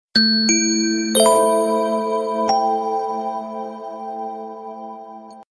Yezz_Attractive_Sms.mp3